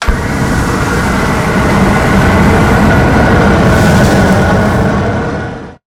flamethrower_shot_01.wav